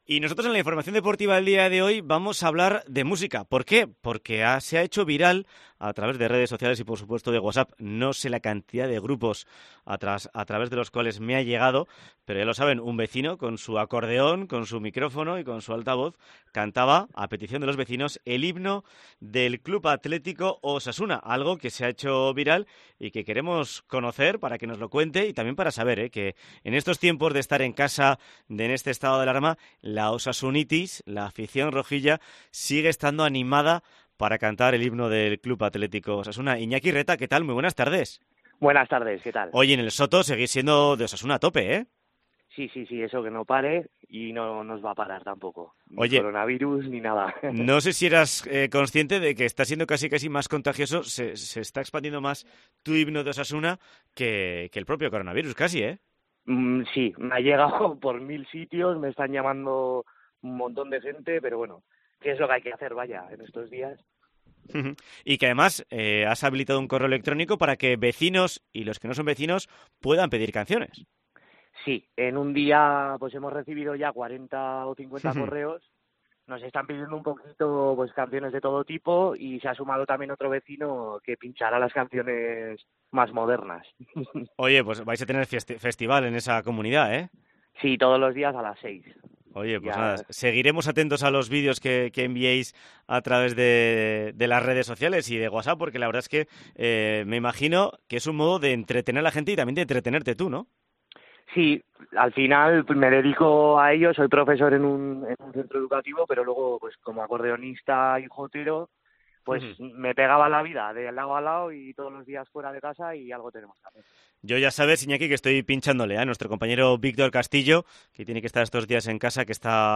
protagonista con su acordeón cantando el himno de Osasuna